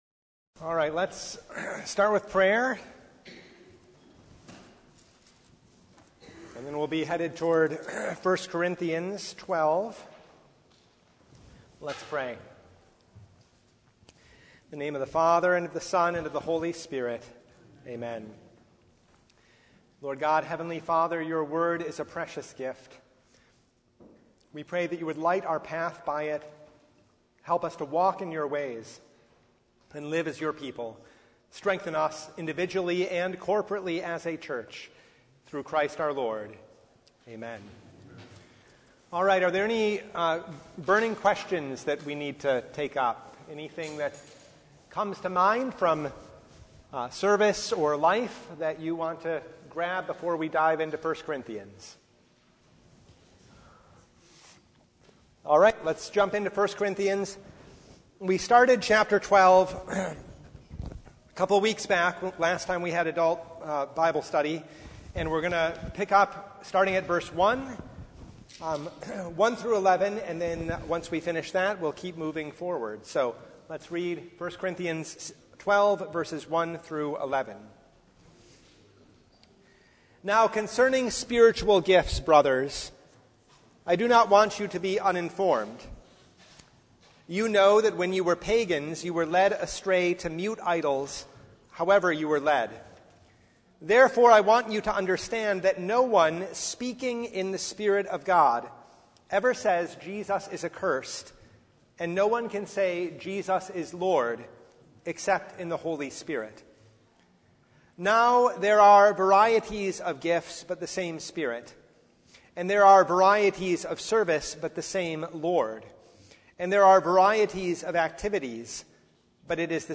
1 Corinthians 12:1-15 Service Type: Bible Hour Topics: Bible Study « The Third Sunday in Advent